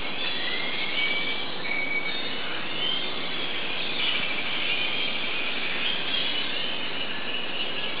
Pt�ci n�m �vou na cestu na ferry - lo� z Puntarenas do Paquera.
puntarenas_morning.wav